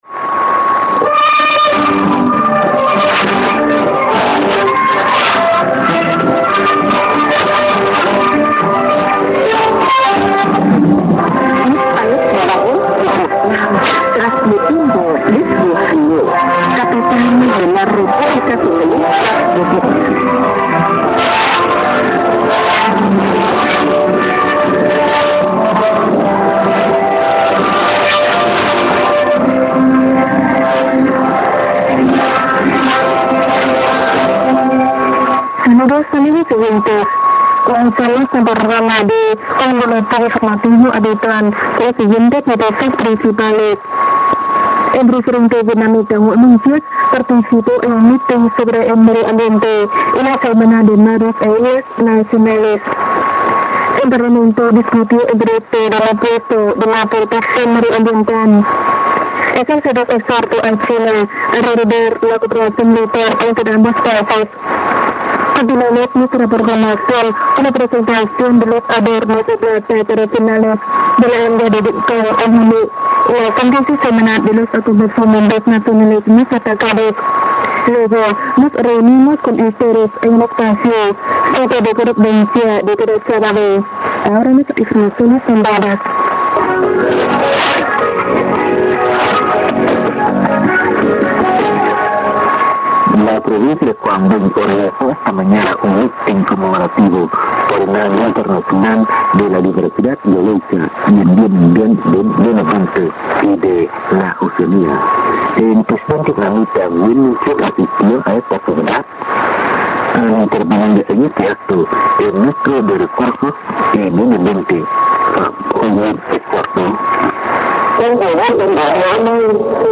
Trechos de áudios de captações realizadas durante o DXCamp Lorena 2010 ocorrido durante os dias 3 e 6 de junho de 2010.
As captações foram realizadas pelos participantes do encontro, utilizando os mais variados equipamentos e antenas.